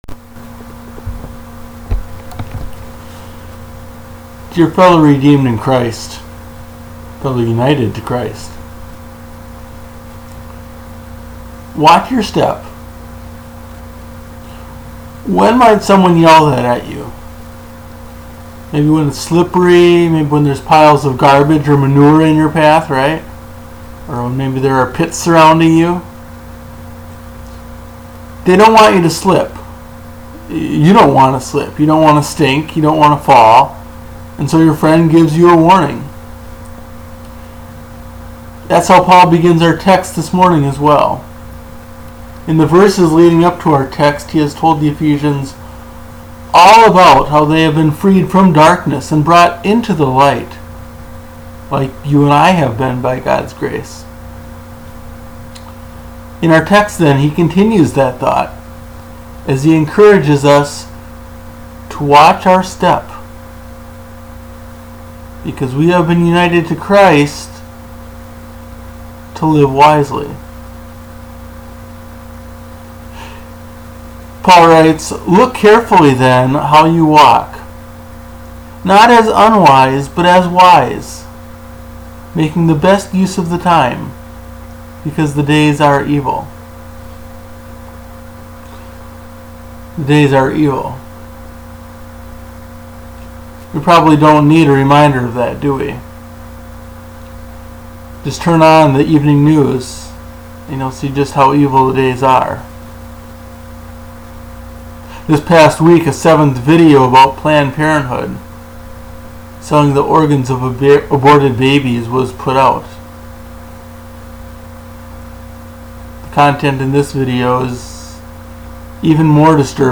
Welcome To Peace Lutheran Church - Sermon Downloads & Podcast